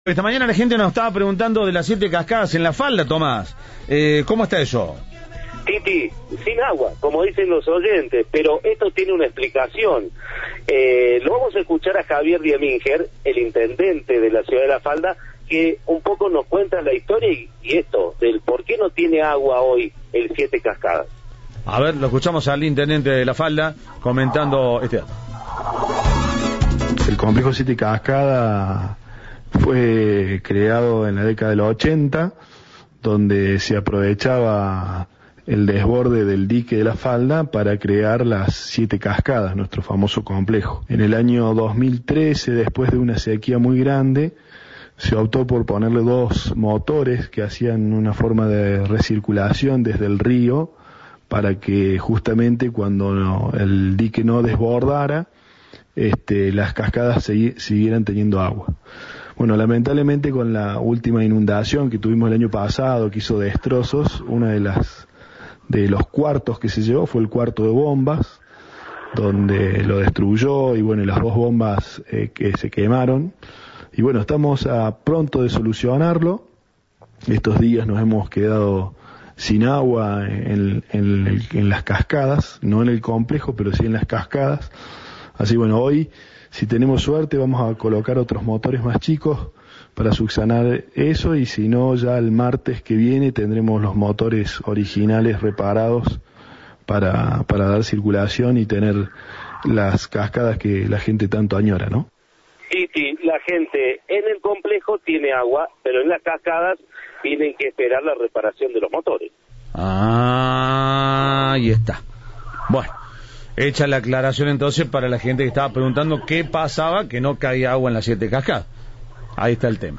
En diálogo con Cadena 3, Javier Dieminger, intendente de la ciudad, explicó que los motores fueron reparados y serán instalados nuevamente.